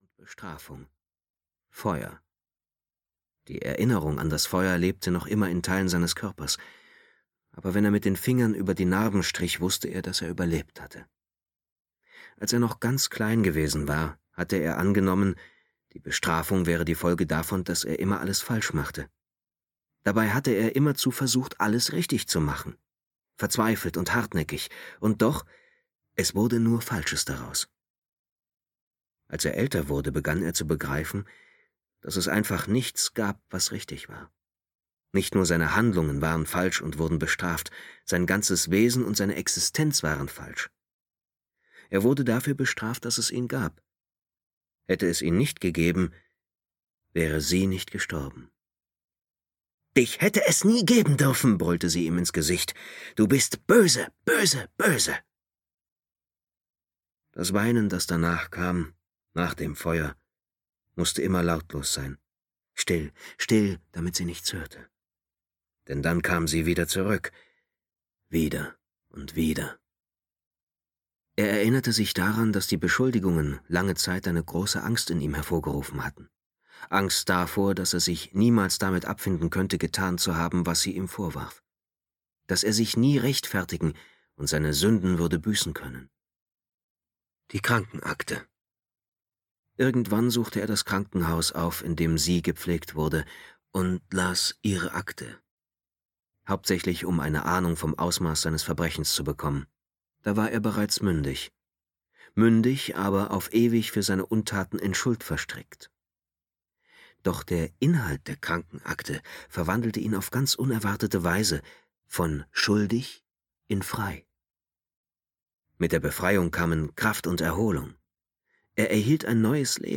Aschenputtel (DE) audiokniha
Ukázka z knihy